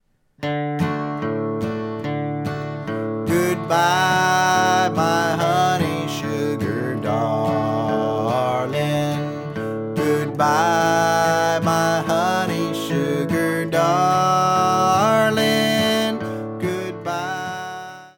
Guitar & voice, medium speed (key of D)